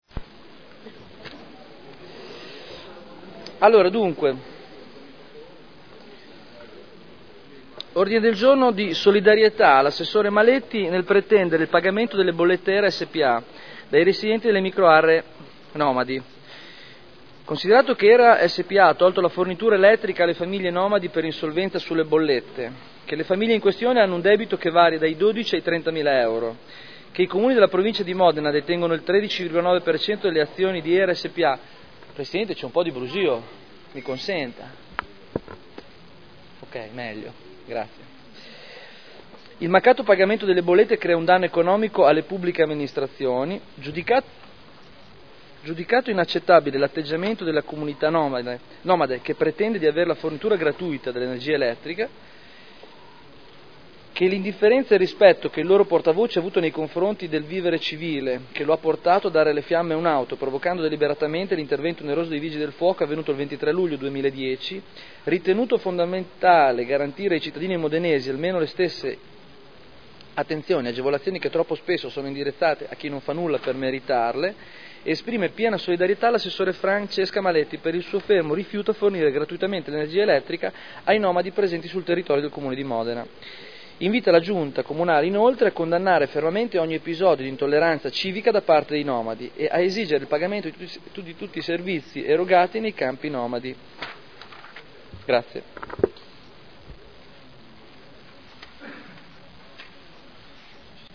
Seduta del 18/04/2011. Introduce Ordine del Giorno presentato dai consiglieri Barberini, Rossi N., Manfredini (Lega Nord) di solidarietà all’assessore Maletti nel pretendere il pagamento delle bollette Hera s.p.a. dai residenti delle micro aree nomadi – Primo firmatario consigliere Barberini